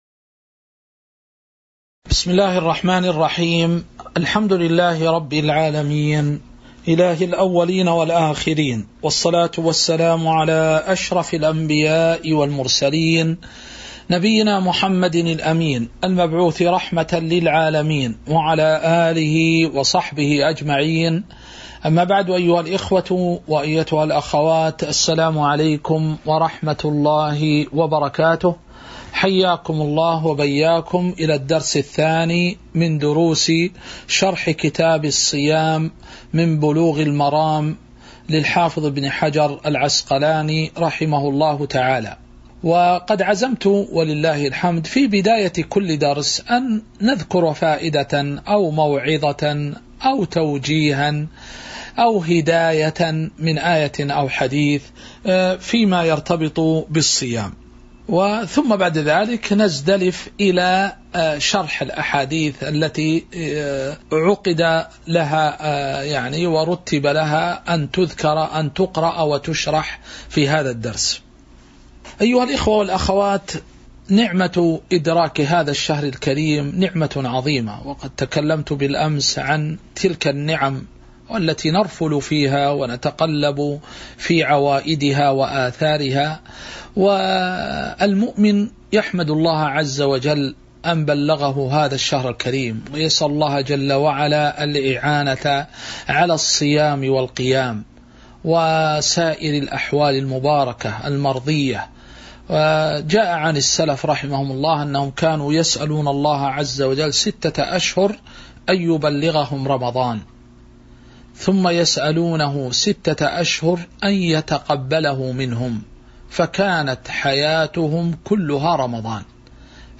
تاريخ النشر ٢٧ شعبان ١٤٤٤ هـ المكان: المسجد النبوي الشيخ